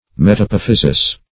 metapophysis - definition of metapophysis - synonyms, pronunciation, spelling from Free Dictionary
Search Result for " metapophysis" : The Collaborative International Dictionary of English v.0.48: Metapophysis \Met`a*poph"y*sis\, n.; pl.